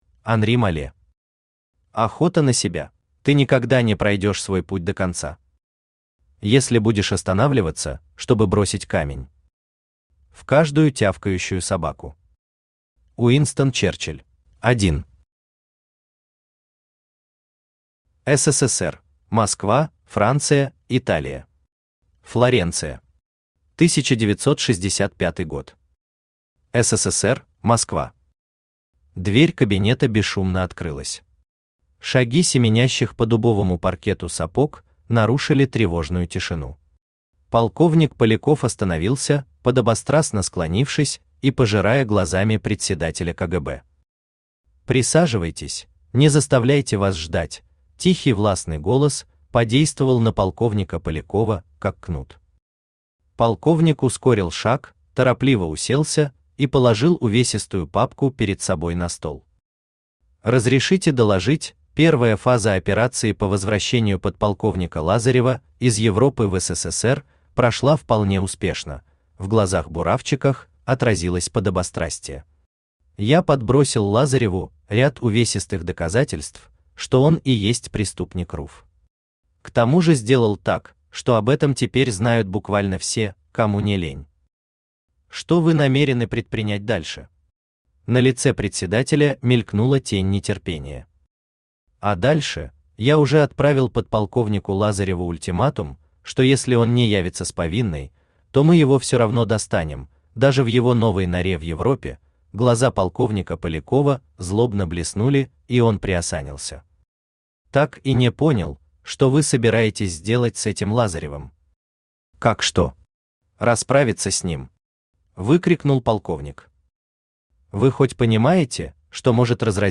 Аудиокнига Охота на себя | Библиотека аудиокниг
Aудиокнига Охота на себя Автор Анри Малле Читает аудиокнигу Авточтец ЛитРес.